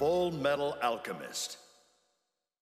kits/Alchemist/Vox/FMA.wav at main